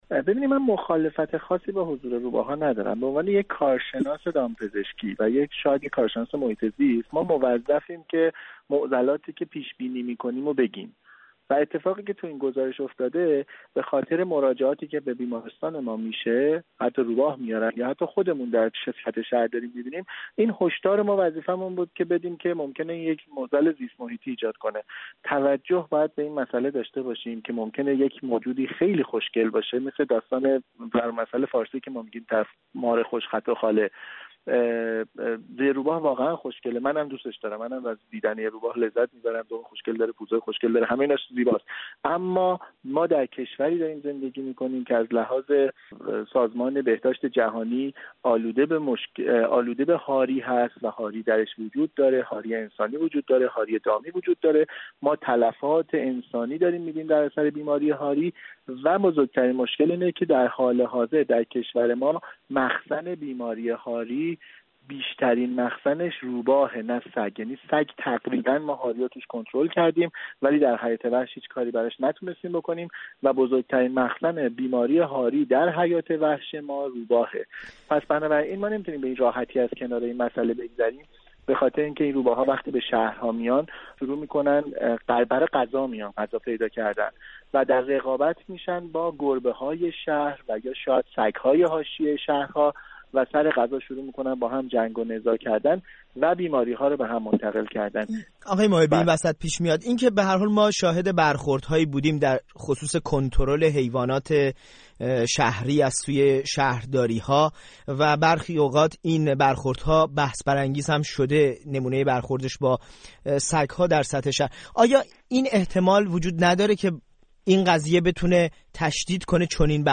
در گفت‌وگو